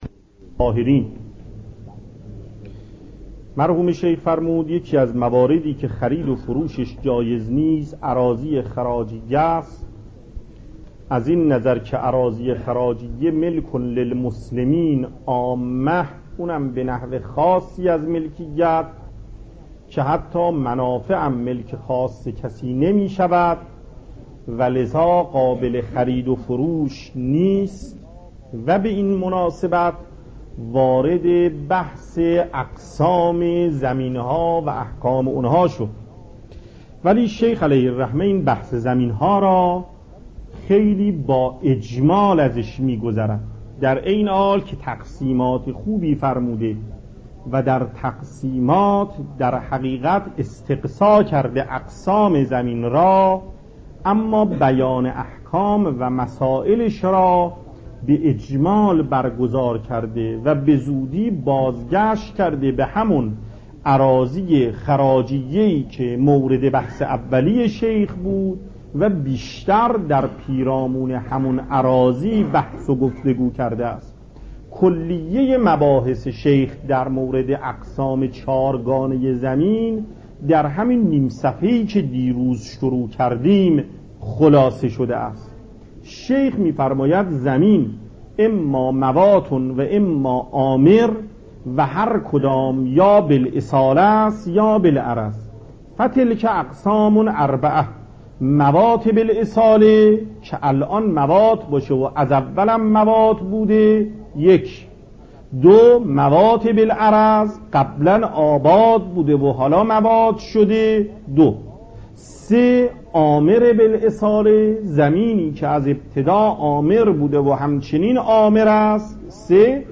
درس فقه آیت الله محقق داماد , درس مکاسب